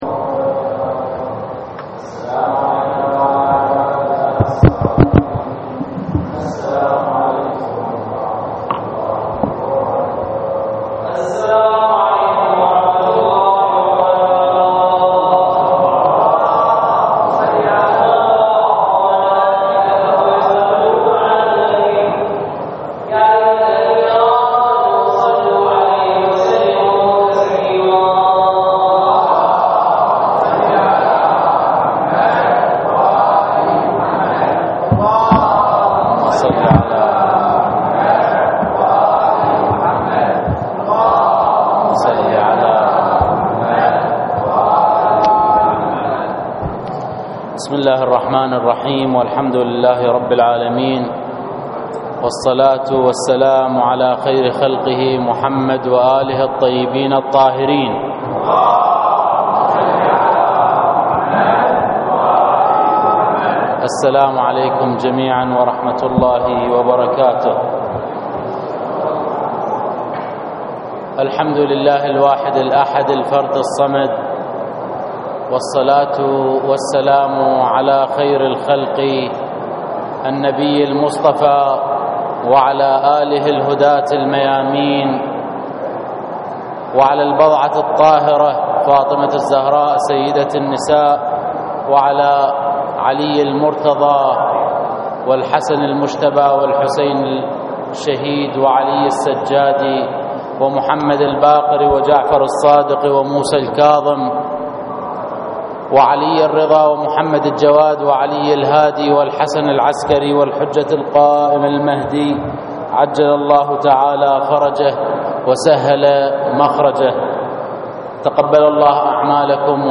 للاستماع الى خطبة صلاة العيد الرجاء اضغط هنا